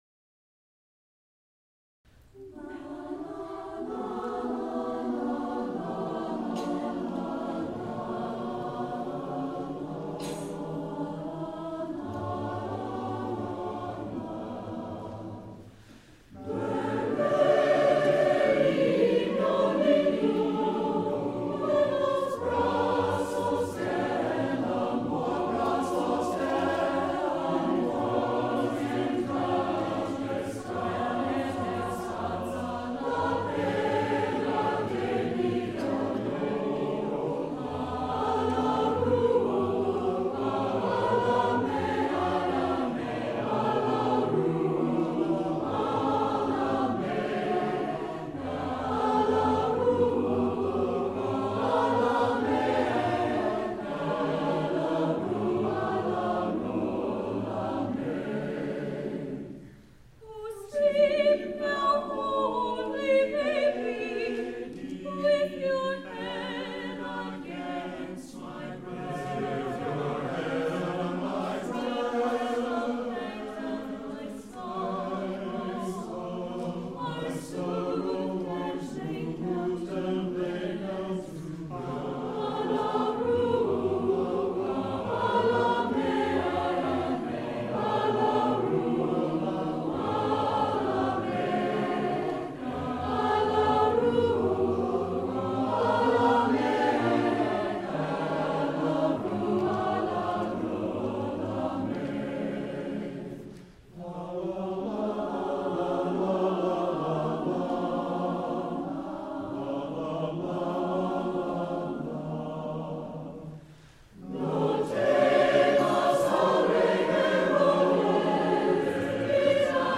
SATB a cappella
This Hispanic Christmas Folk Song